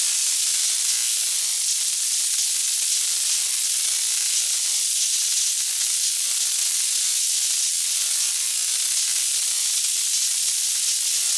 Electric SparksLoop.ogg